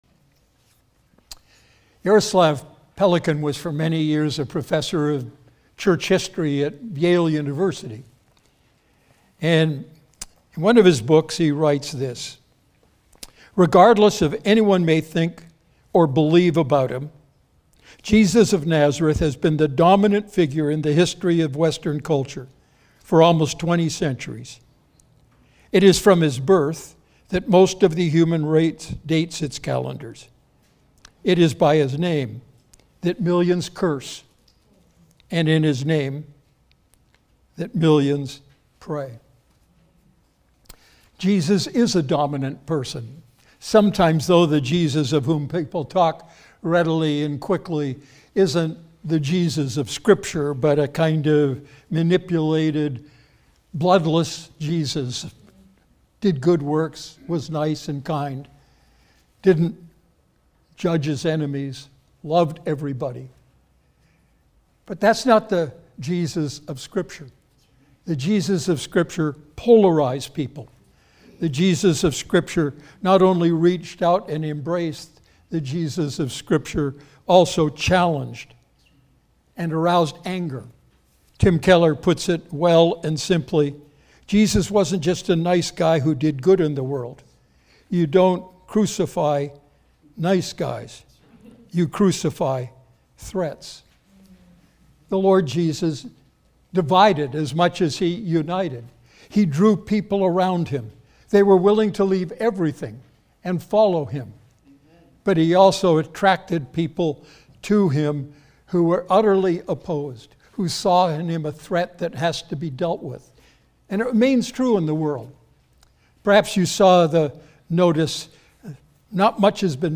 Sermon Archive, Redeemer Fellowship